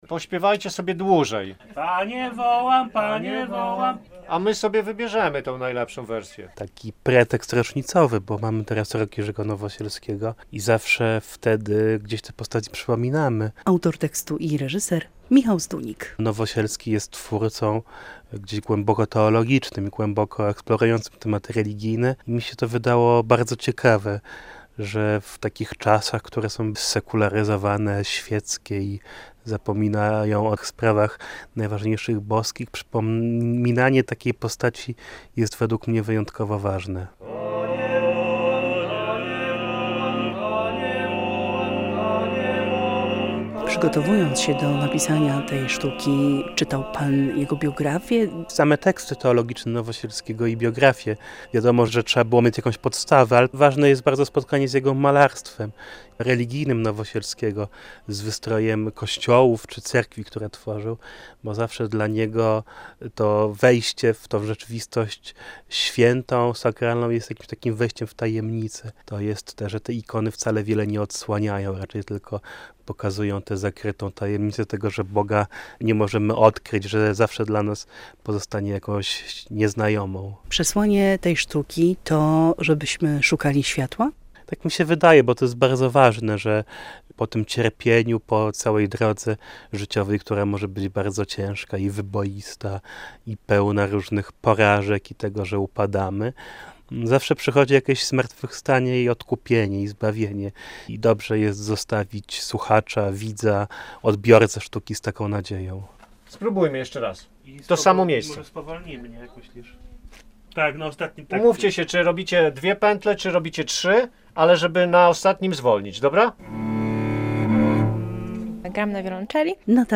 "Głosy ciemności" - w Studiu Rembrandt Radia Białystok powstaje nowe słuchowisko